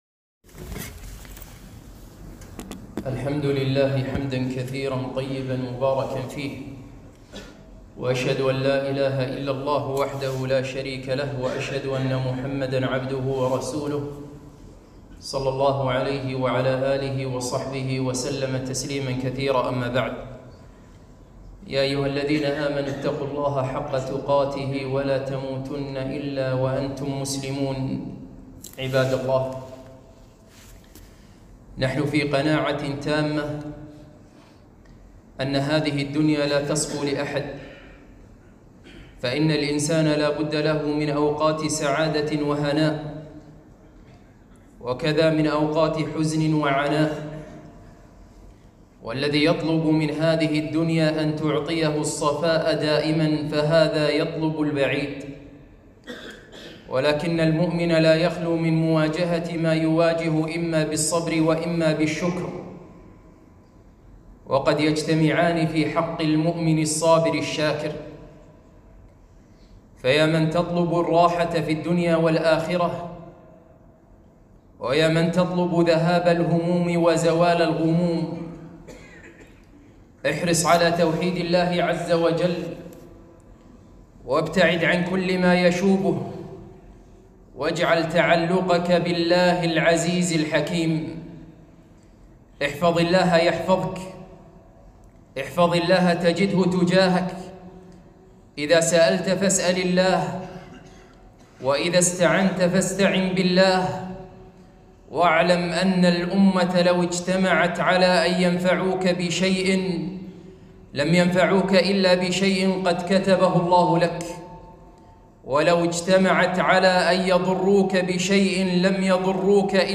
خطبة - رسالة لمن أصابه الهم والحزن